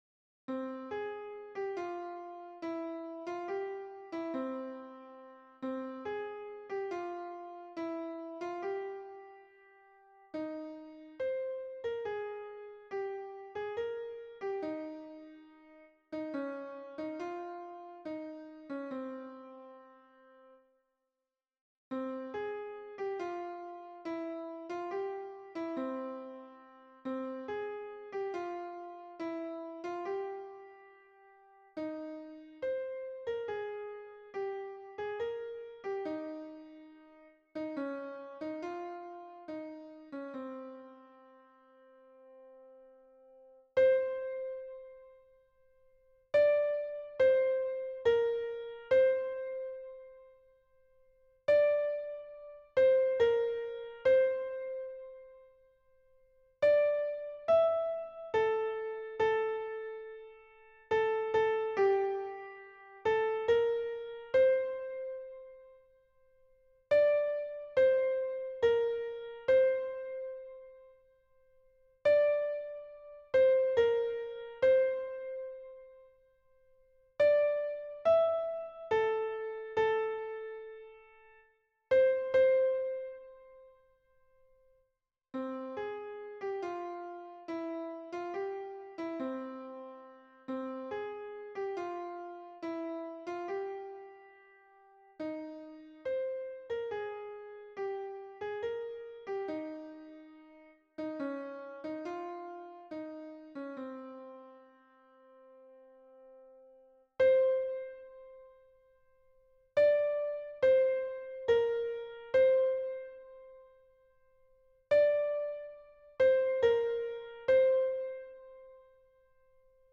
MP3 version piano
Soprano